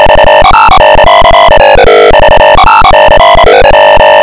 Up-a-year tune